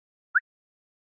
Звуки Gmail уведомлений скачать - Zvukitop
12. whistle
gm-whistle.mp3